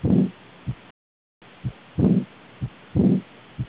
aorticinsufficiency.wav